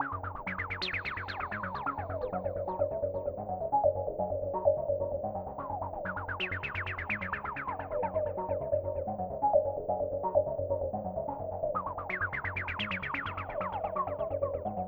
TEC Acid Riff E-Ab-D-Eb-Db.wav